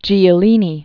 (jēə-lēnē, j-lē-), Carlo Maria 1914-2005.